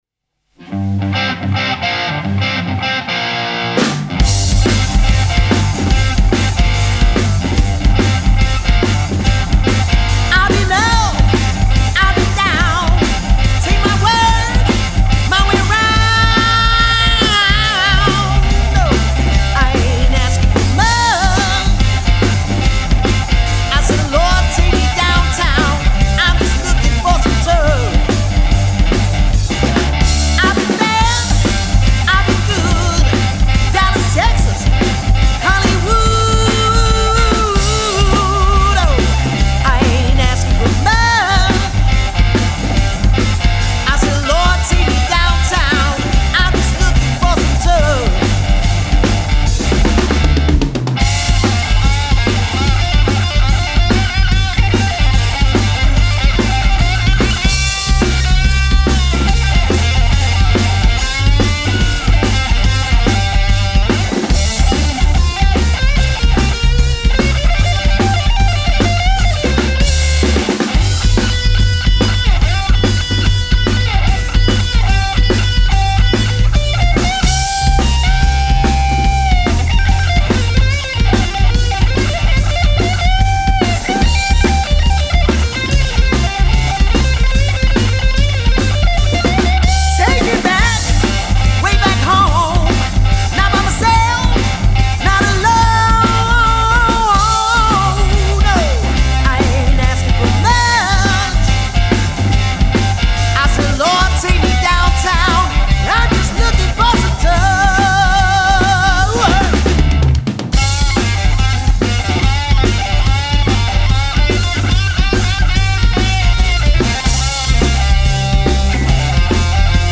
are a classic rock/metal covers band based in Bristol.